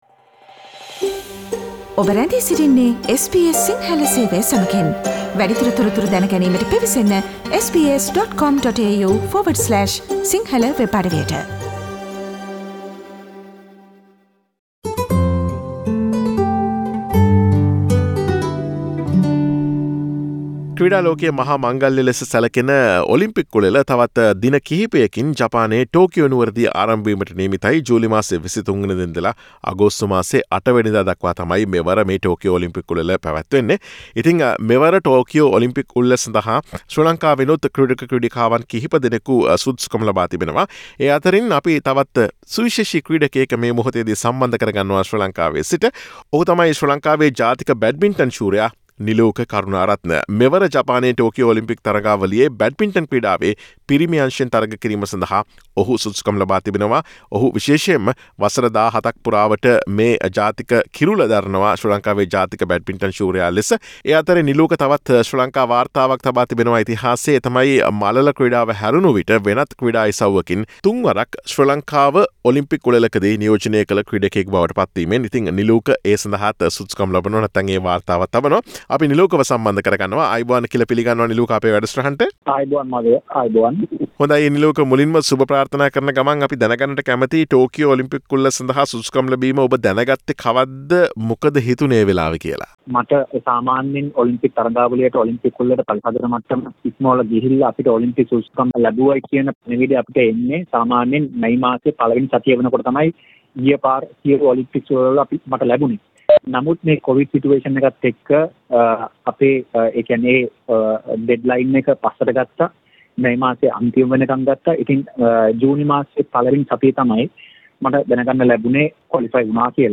Listen to SBS Sinhala Radio's interview with Sri Lankan badminton champion Niluka Karunaratne, who has qualified for the Tokyo Olympics in Japan this year.